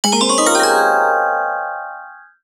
UI_SFX_Pack_61_36.wav